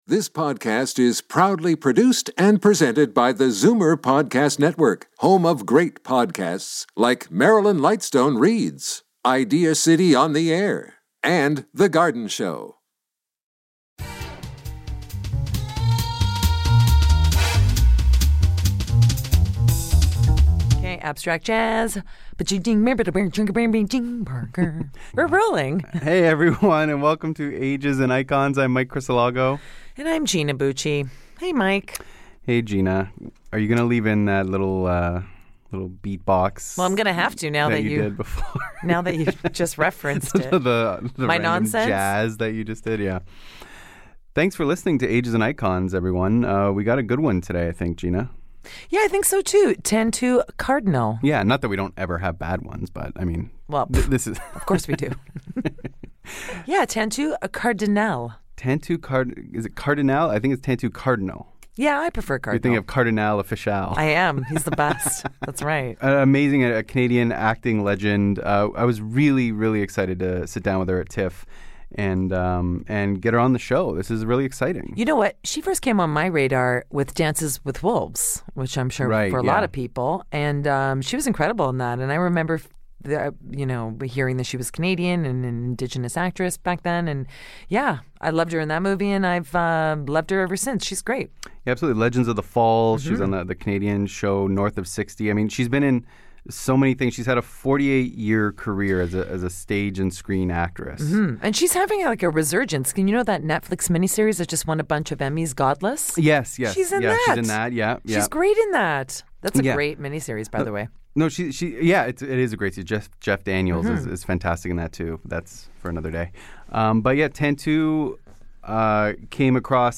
sits down with legends from stage and screen, literature, music and more...